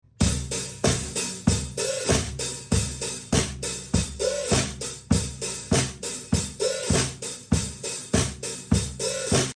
Drum pedals.